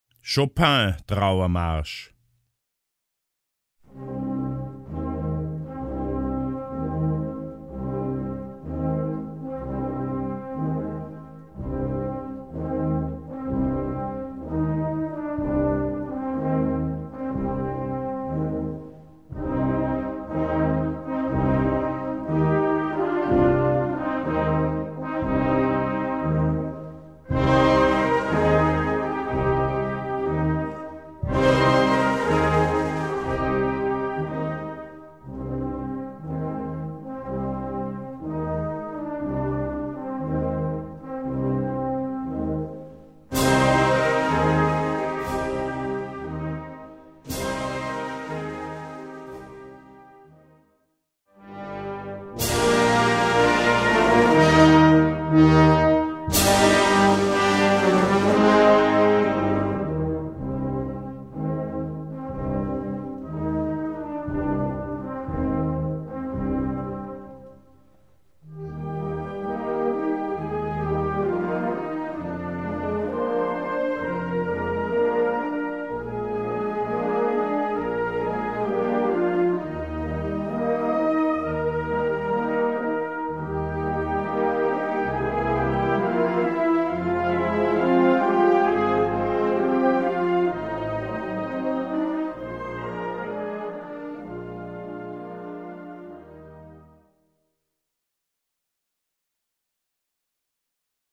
Gattung: Trauermarsch
Besetzung: Blasorchester